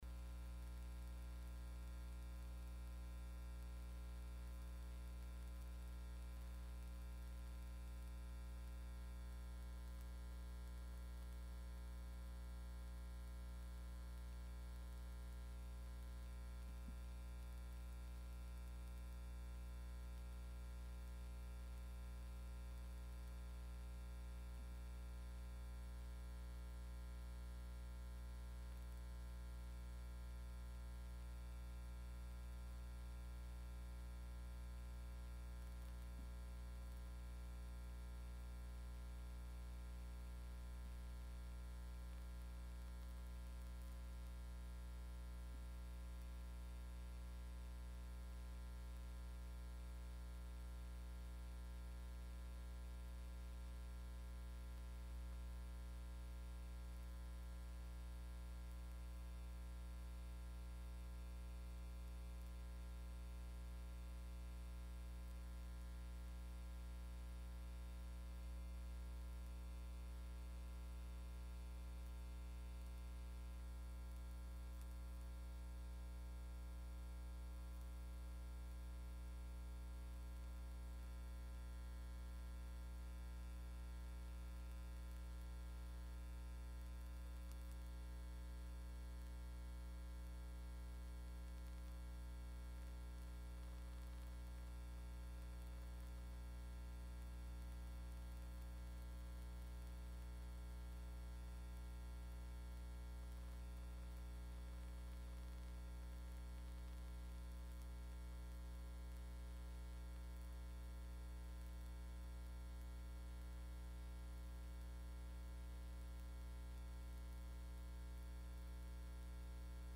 8ª Audiência Pública